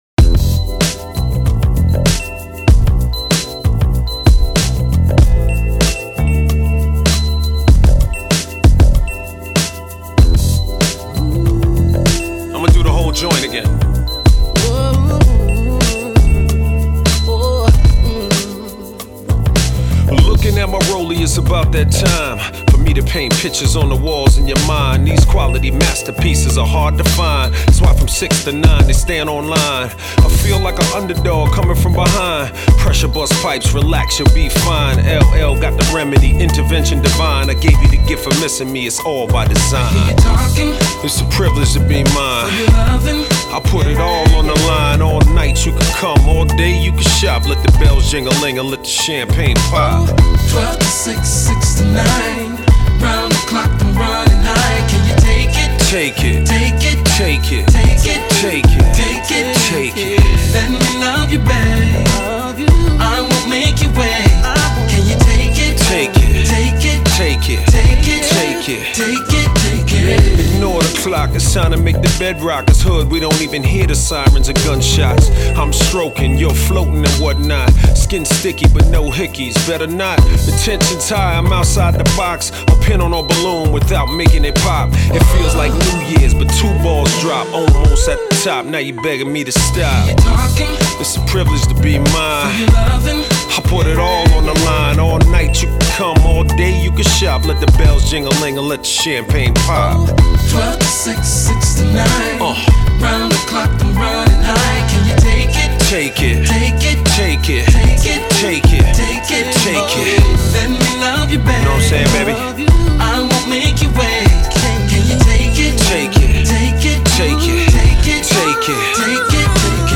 a butter-smooth template